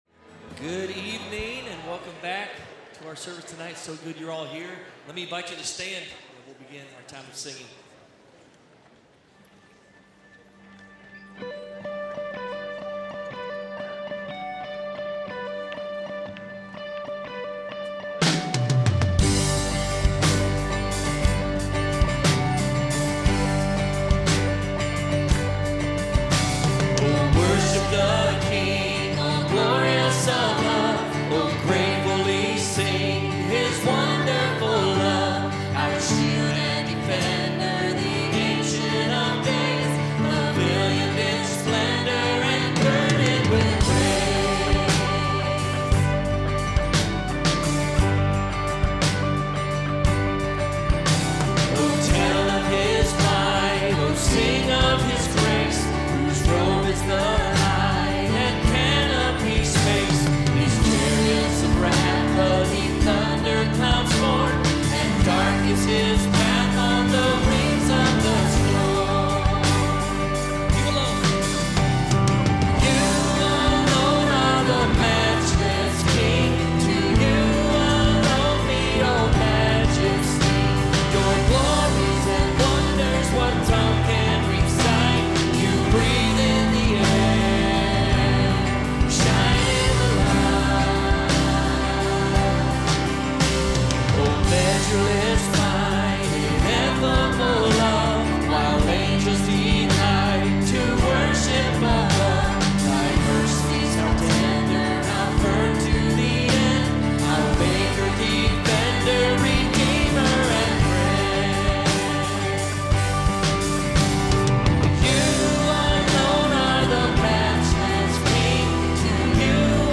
Evening Worship Service